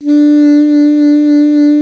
55AF-SAX05-D.wav